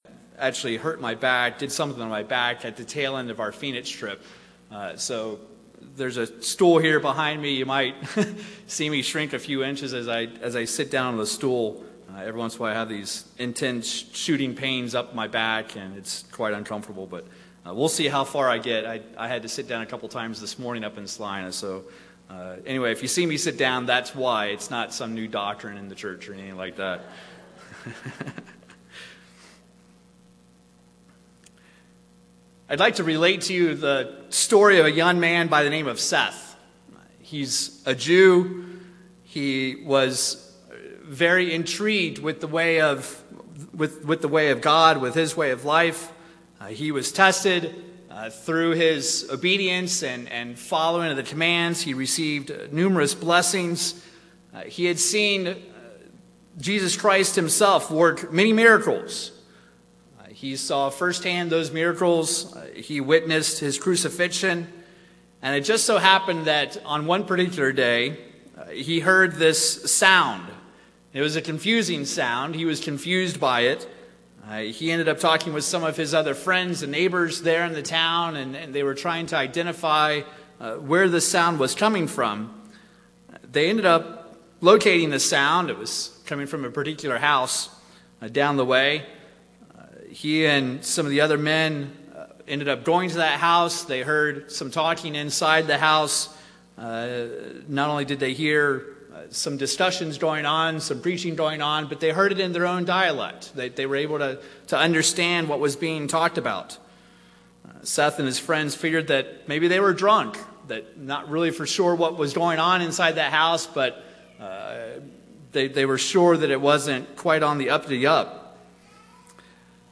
These are the notes taken live during services as captioning for the deaf and hard of hearing.
UCG Sermon Notes These are the notes taken live during services as captioning for the deaf and hard of hearing.